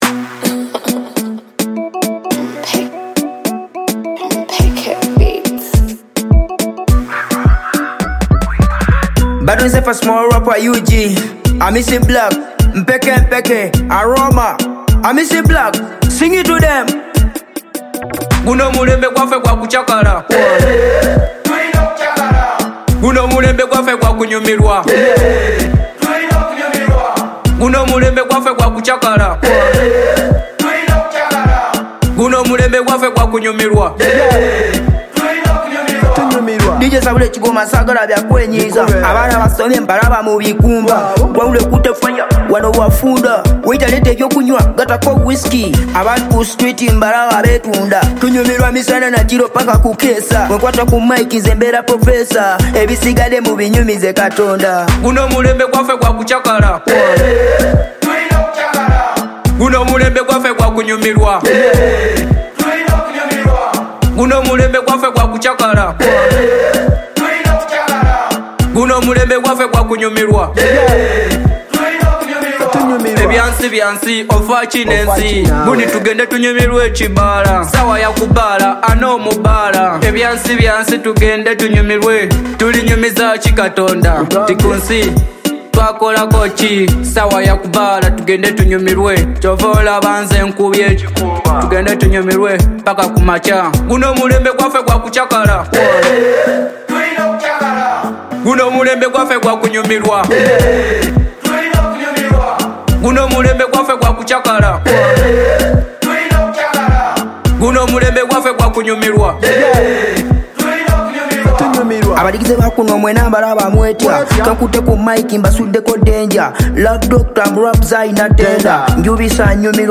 Teso music
ambient melodies